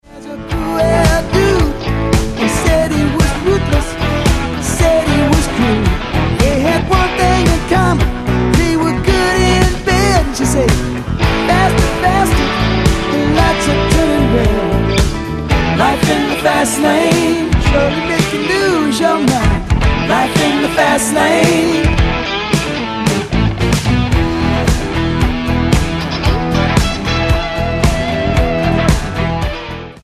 Genre: Pop Rock